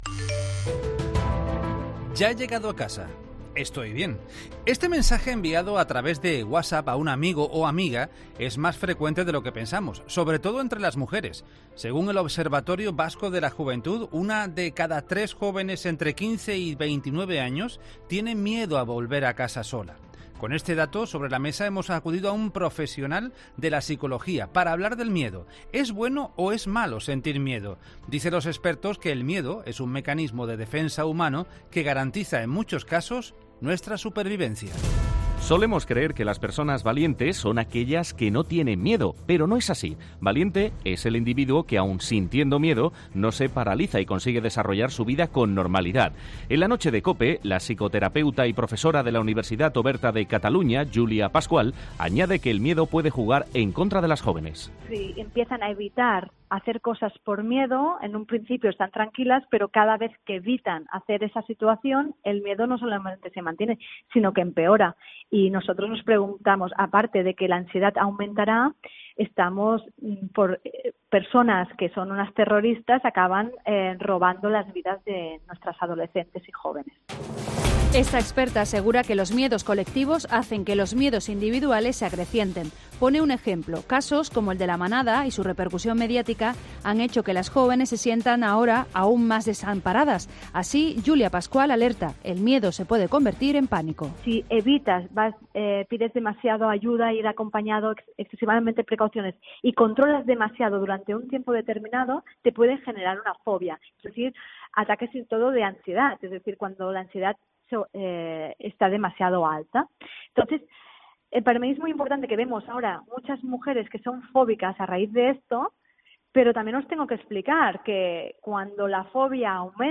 Con este dato sobre la mesa, hemos acudido a un profesional de la psicología para hablar del miedo.